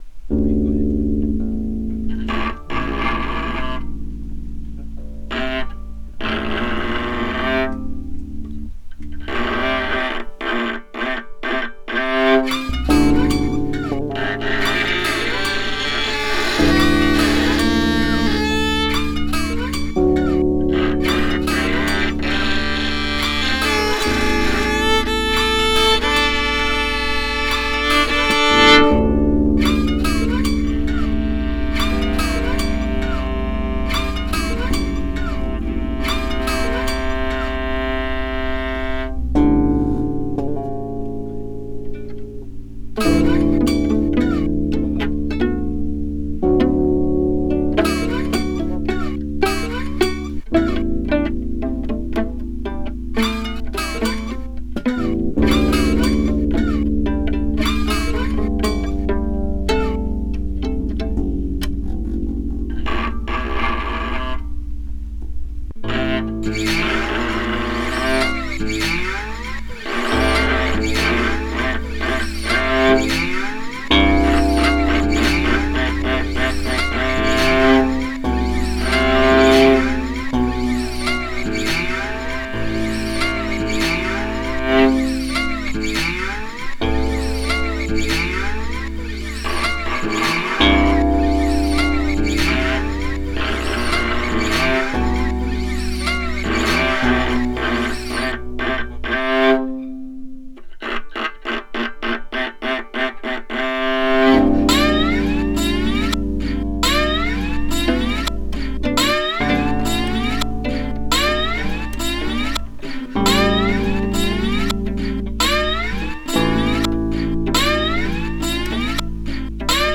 drums, electronic drums, synths, effects, programming